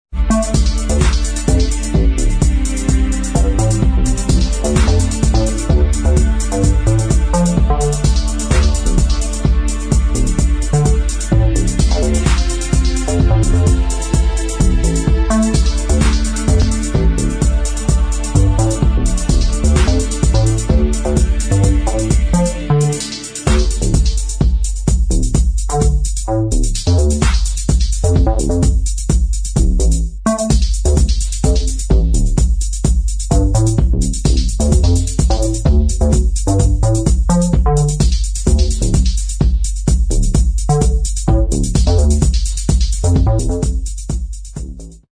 [ DEEP HOUSE ]
シカゴ～デトロイトを通過したダブ・ハウス・サウンド！！